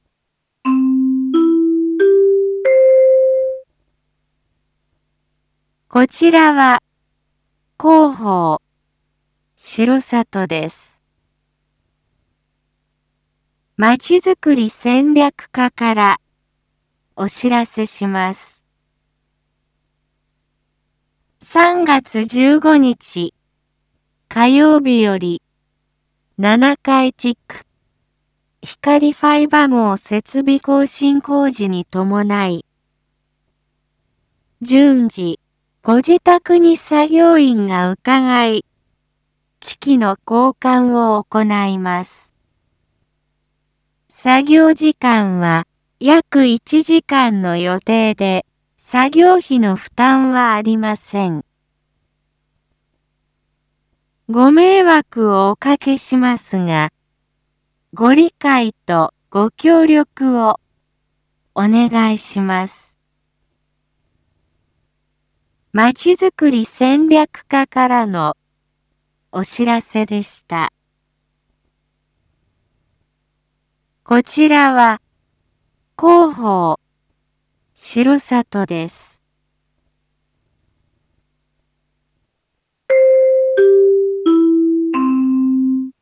Back Home 一般放送情報 音声放送 再生 一般放送情報 登録日時：2022-03-10 19:01:27 タイトル：R4.3.10 19時 放送分 インフォメーション：こちらは広報しろさとです。